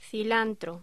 Locución: Cilantro